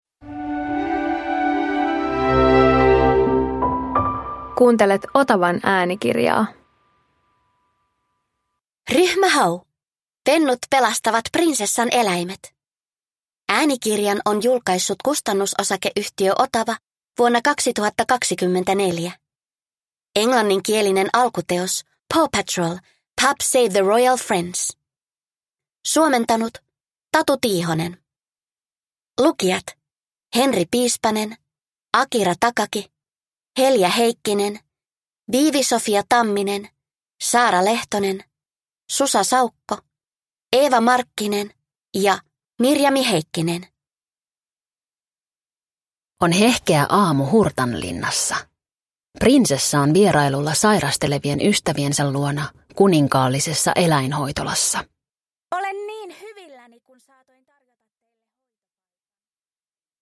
Ryhmä Hau - Pennut pelastavat prinsessan eläimet – Ljudbok